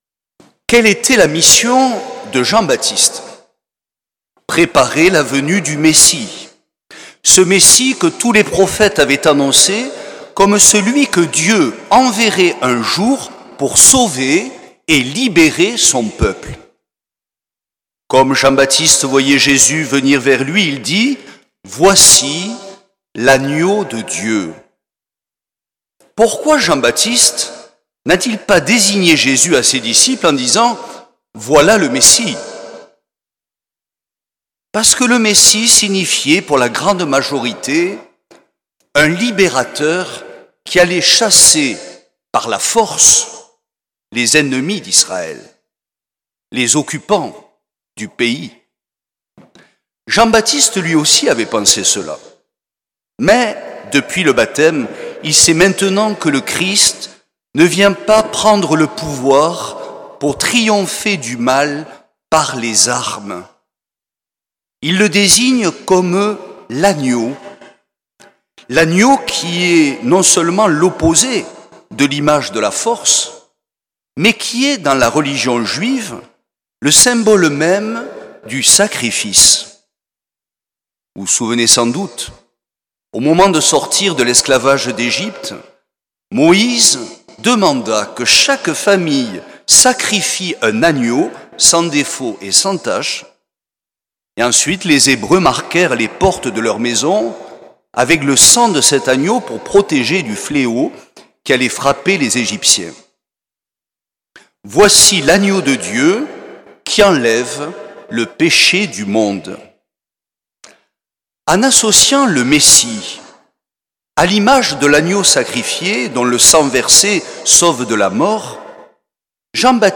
L’enregistrement retransmet l’homélie